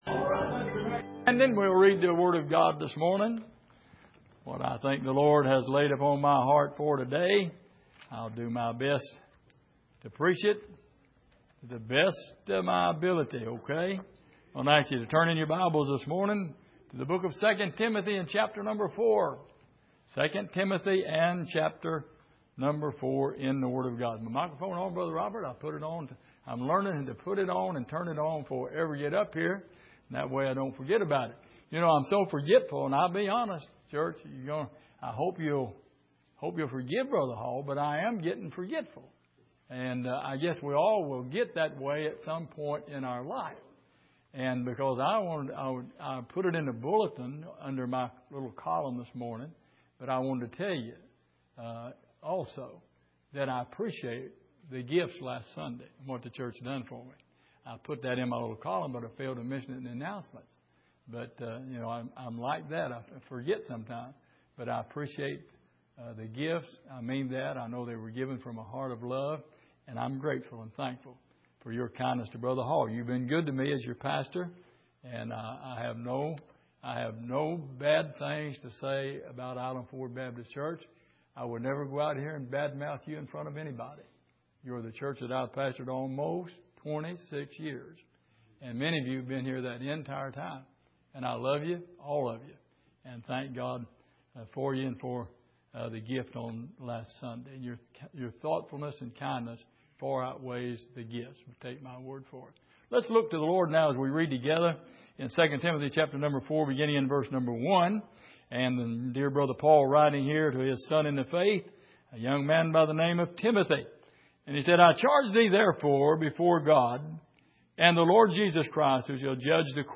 Miscellaneous Passage: 2 Timothy 4:1-9 Service: Sunday Morning Some Things That We Have Lost « Lord